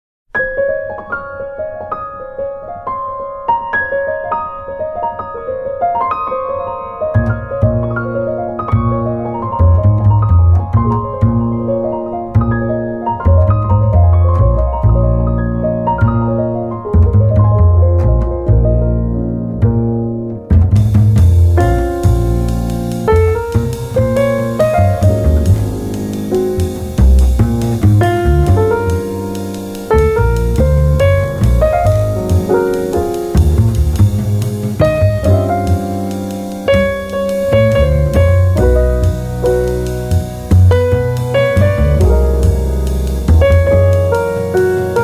piano
bass
drums
美しきクラシックのスタンダード・チューンをス インギンなジャズのフィーリングで力強く、そして優雅にプレイ！